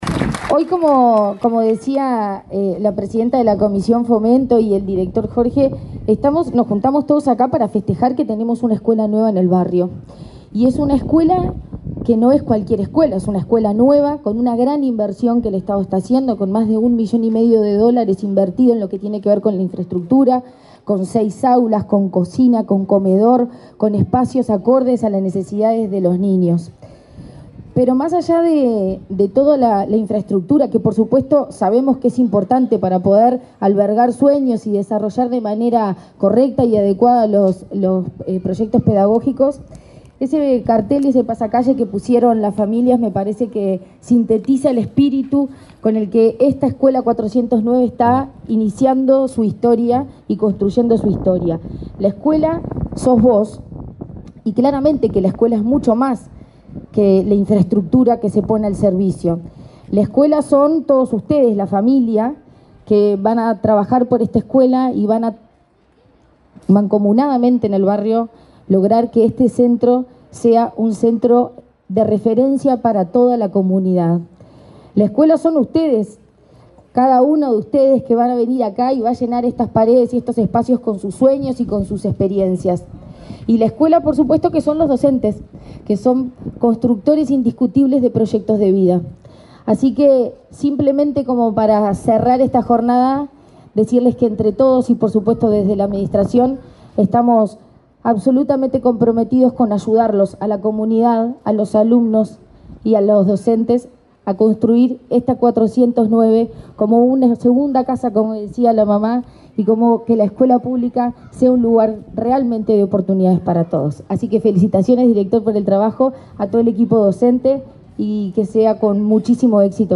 Palabras de la presidenta de ANEP, Virginia Cáceres
La presidenta de la Administración Nacional de Educación Pública (ANEP), Virginia Cáceres, participó, este viernes 12 en Montevideo, en la